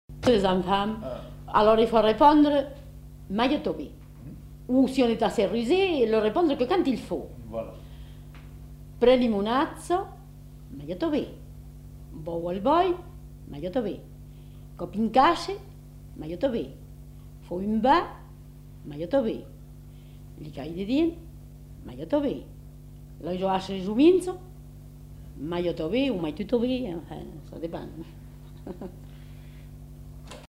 Aire culturelle : Périgord
Lieu : Castels
Genre : conte-légende-récit
Type de voix : voix de femme
Production du son : parlé
Classification : contes-attrape